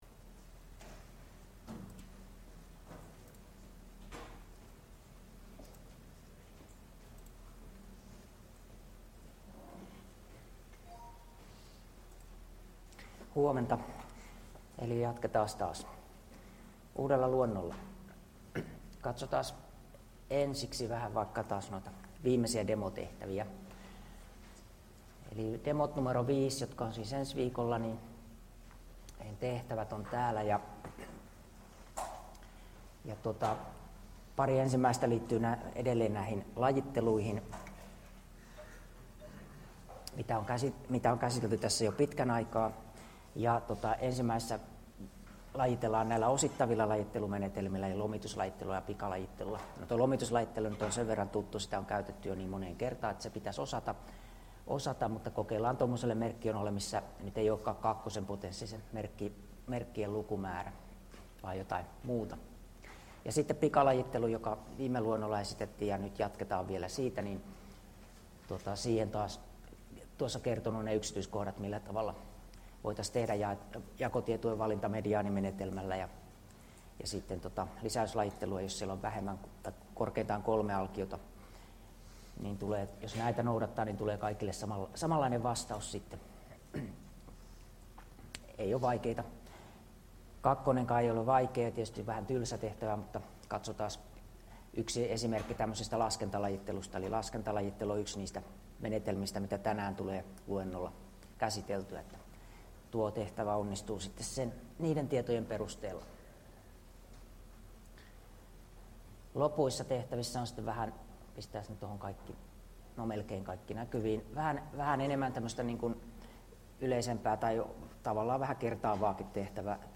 Luento 12 — Moniviestin